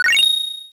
click-short-confirm.wav